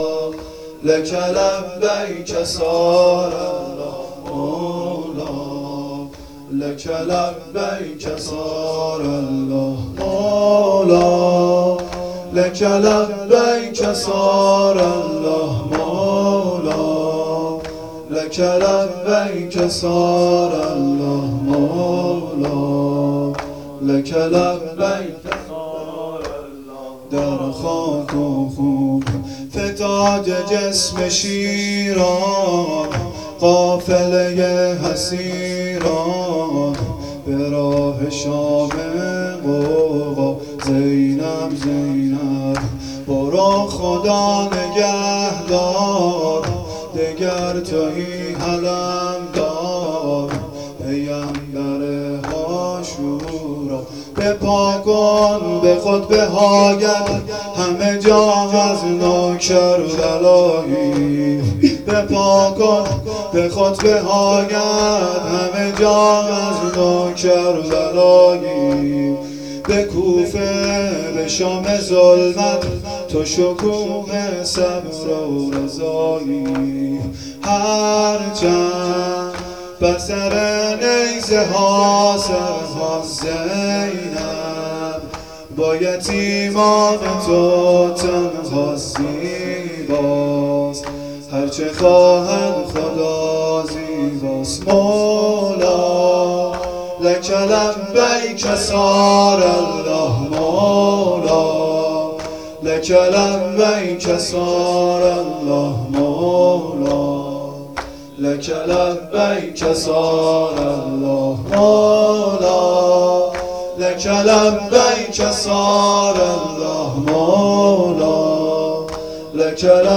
مراسم هفتگی آذر ماه 1397